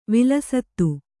♪ vilasattu